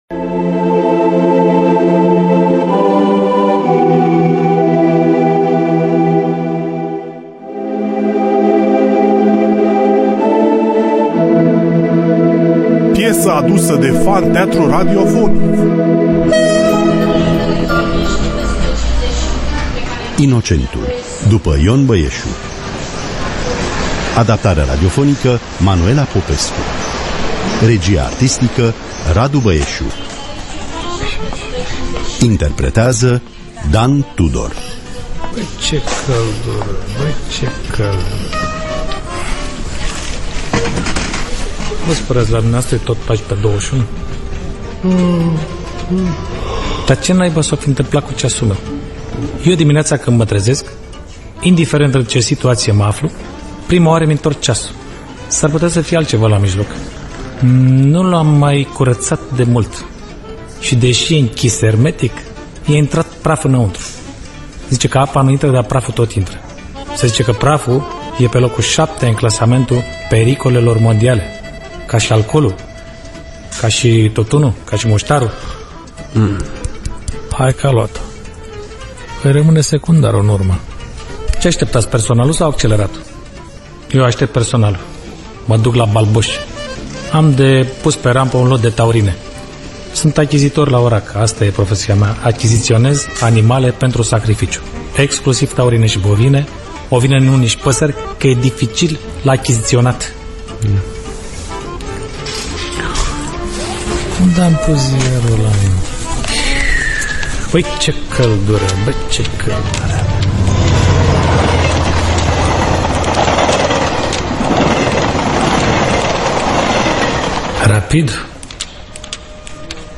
Adaptarea radiofonică